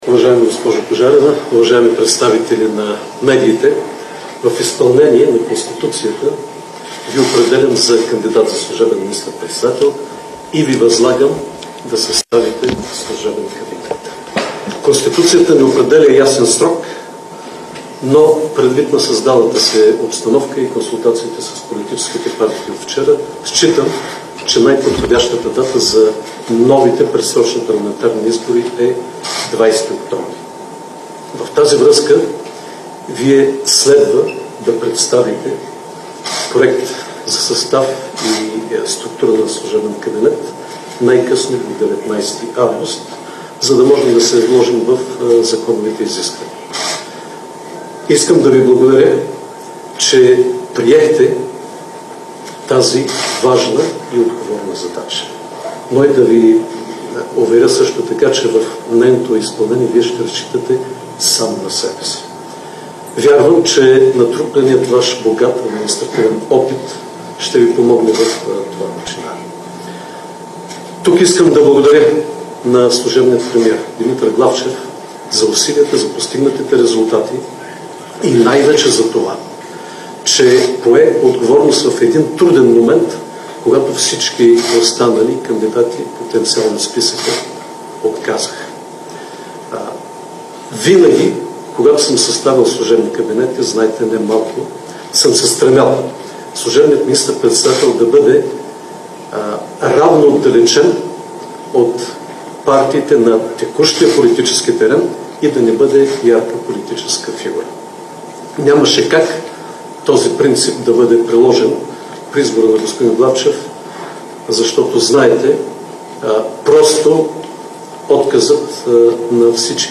9.40 - Брифинг на председателя на ДПС Делян Пеевски.
Директно от мястото на събитието